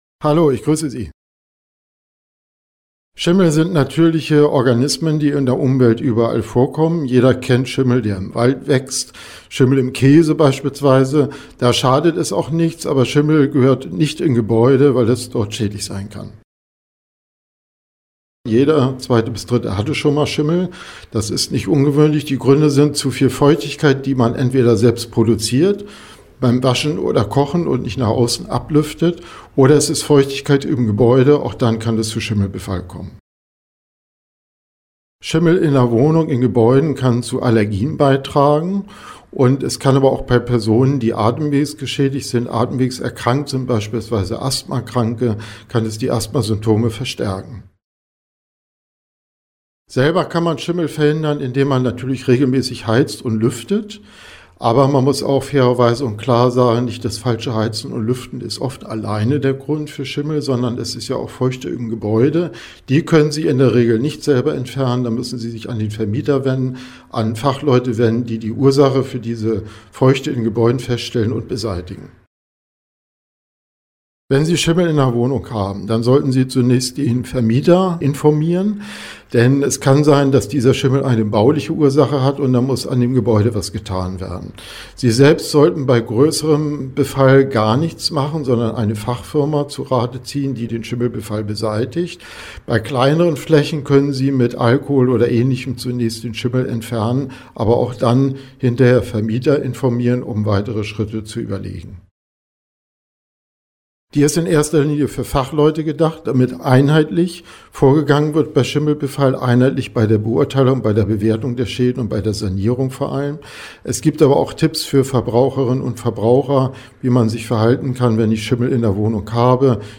Interview: 2:06 Minuten